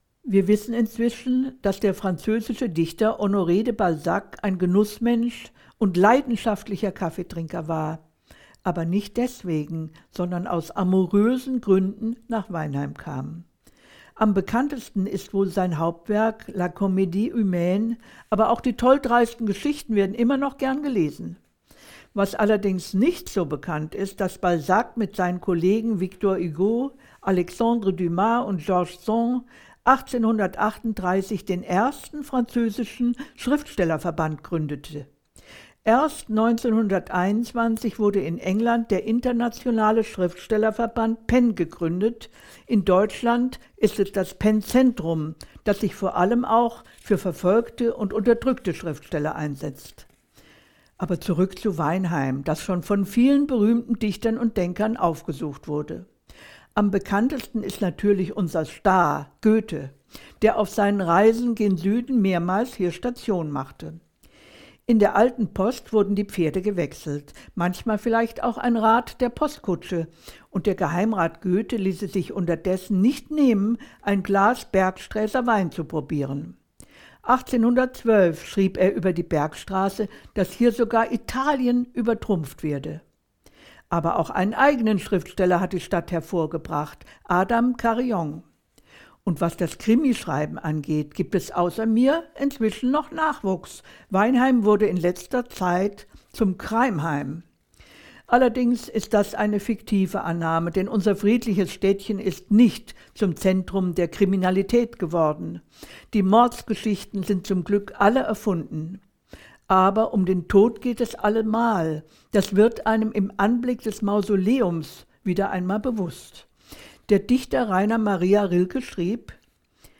In ihrem ganz eigenen Stil und mit einem augenzwinkernd-subtilen Humor führt sie dann den Begleiter von Ort zu Ort.